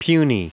Prononciation du mot puny en anglais (fichier audio)
Prononciation du mot : puny